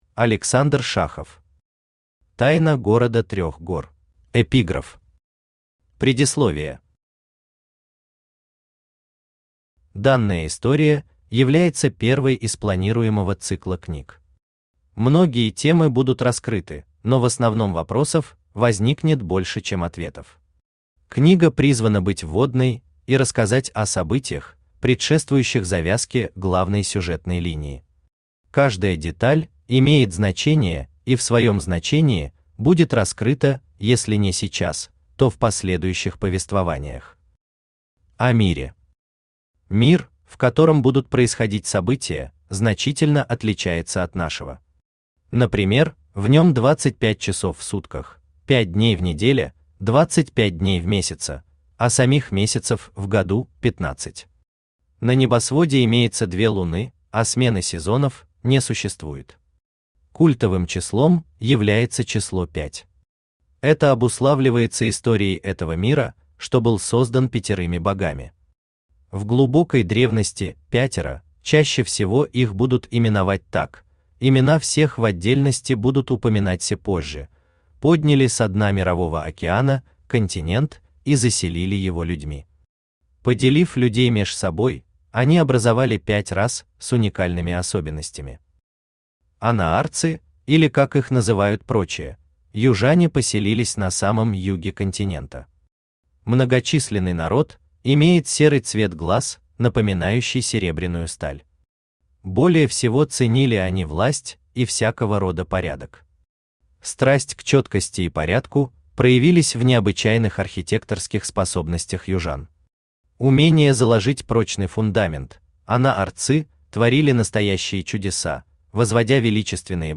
Аудиокнига Тайна города трёх гор | Библиотека аудиокниг
Aудиокнига Тайна города трёх гор Автор Александр Александрович Шахов Читает аудиокнигу Авточтец ЛитРес.